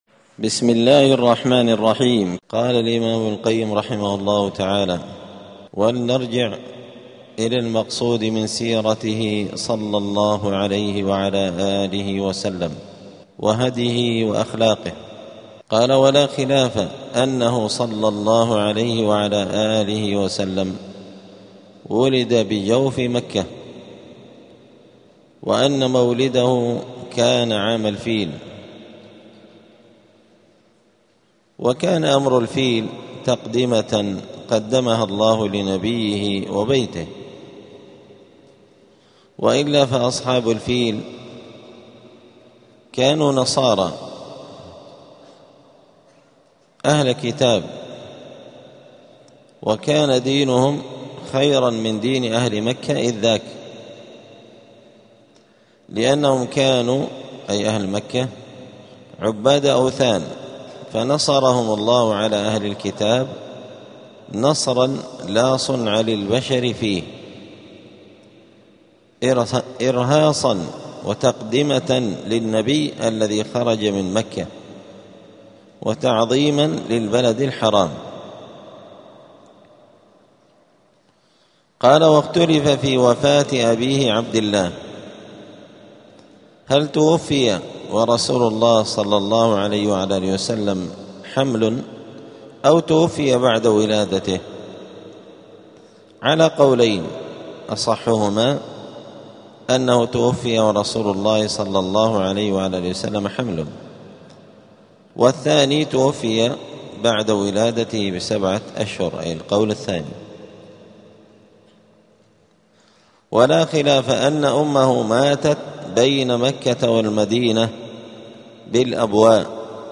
*الدرس الثاني عشر (12) {مولد النبي وعام مولده}.*